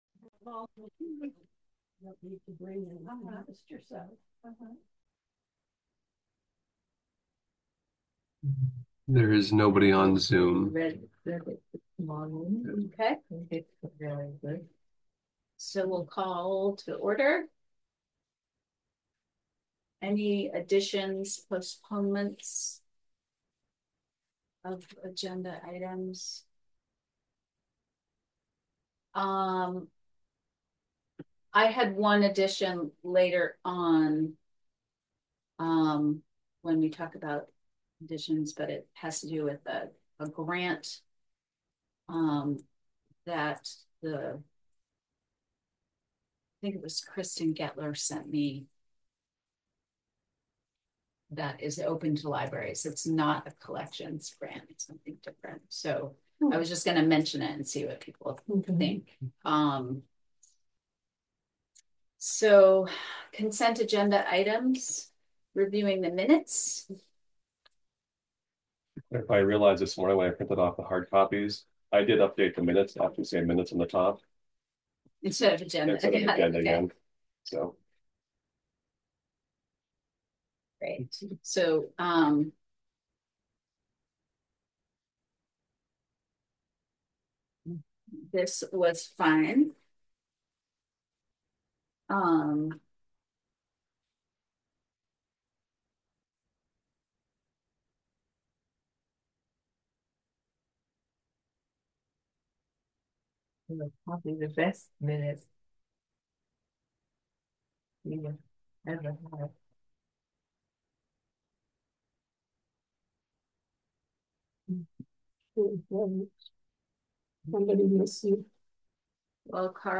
April 10 2025: RFL Board Meeting - Roxbury Free Library